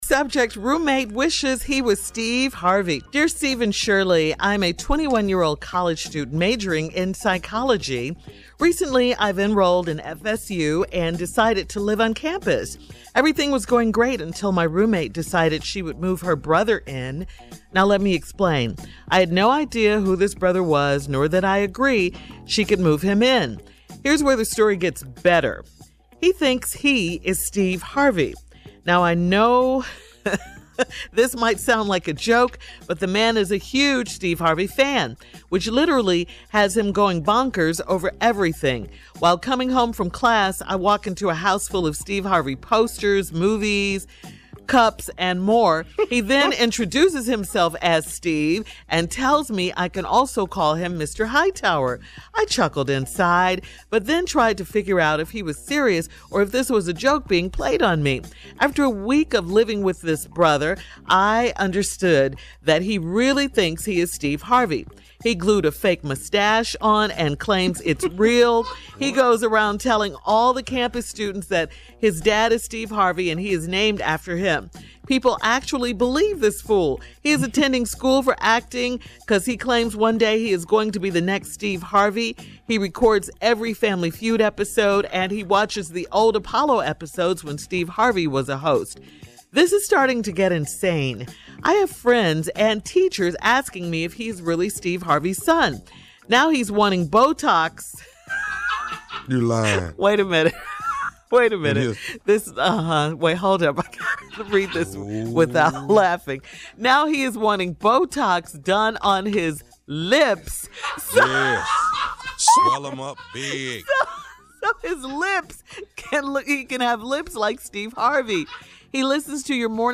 Listen to Shirley and Steve respond to this letter below: